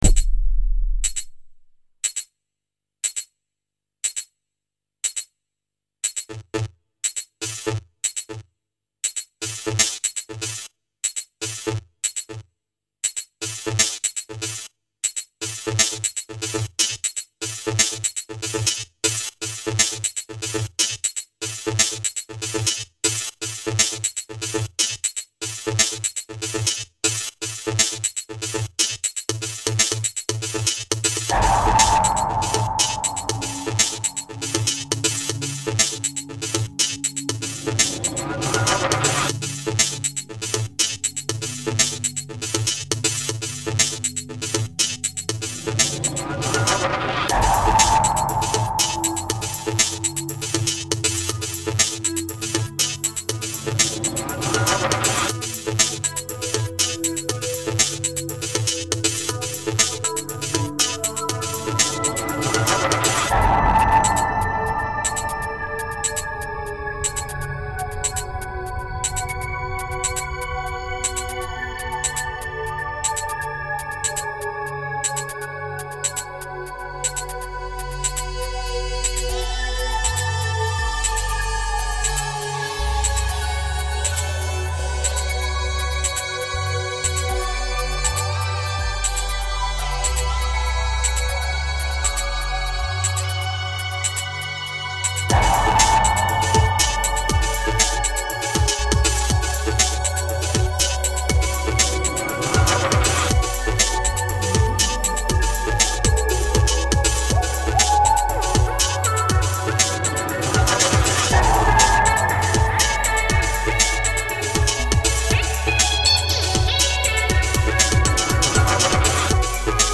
ambient to light techno sound